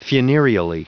Prononciation du mot funereally en anglais (fichier audio)
Prononciation du mot : funereally